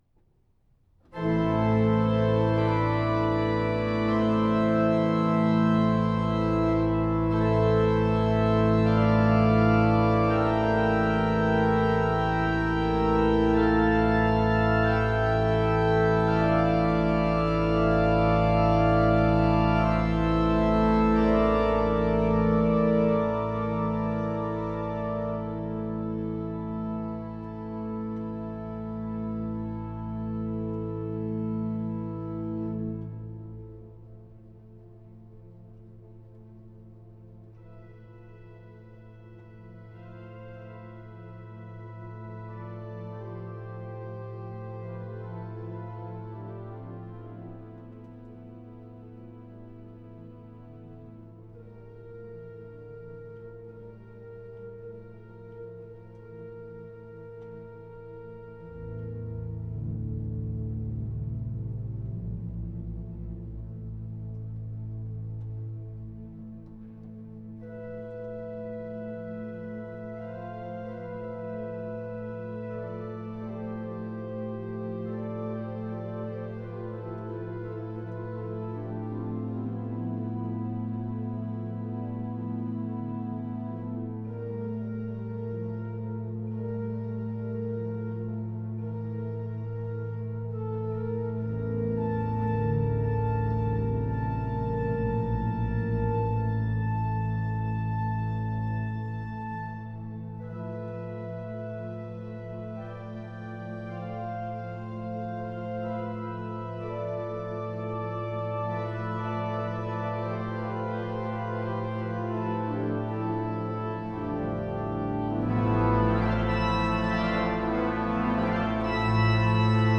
The recording has not been edited
well-known organist